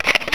Index of /phonetones/unzipped/LG/KH1200/Event sounds
Shutter3.wav